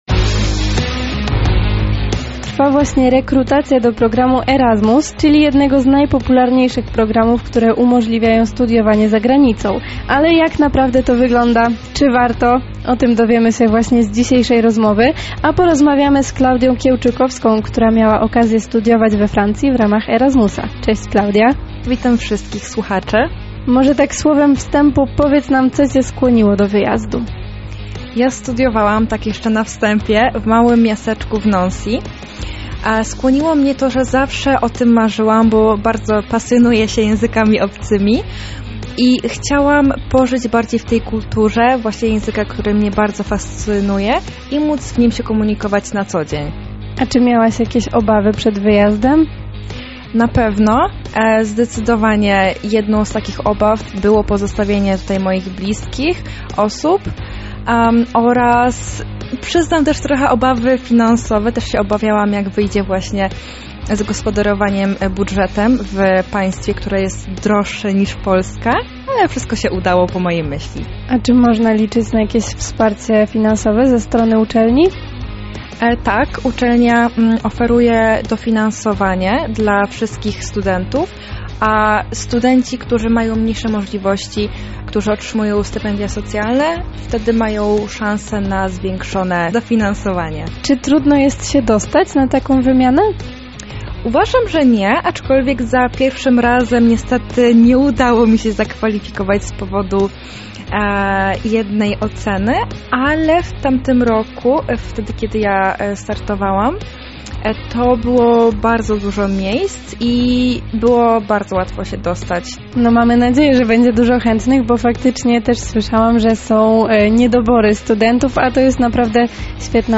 Ten artykuł oraz rozmowa przeprowadzona ze studentką po wymianie mogą rozwiać Twoje wątpliwości.